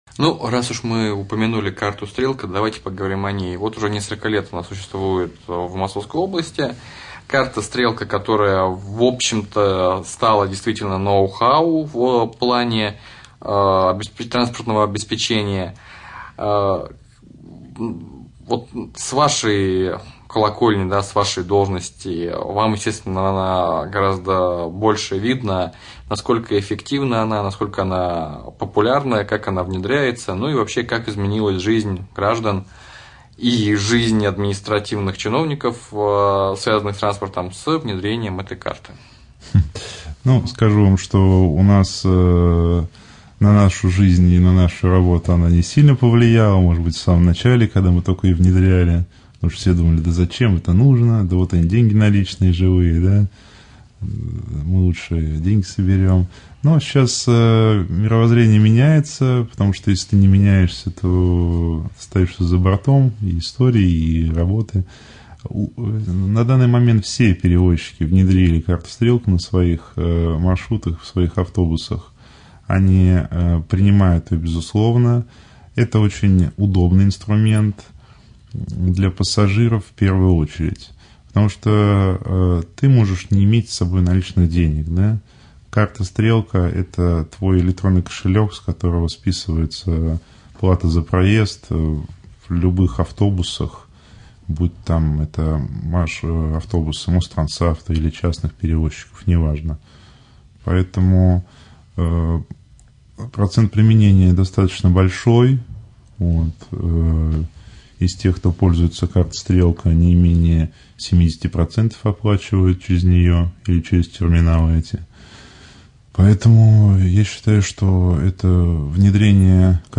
Все автотранспортные предприятия, в том числе частные, осуществляющие пассажирские перевозки на территории Раменского района, принимают к оплате за проезд карту «Стрелка». Об этом в ходе прямого эфира на Раменском радио рассказал начальник Управления инвестиций, транспорта и связи администрации Раменского района Андрей Скибо.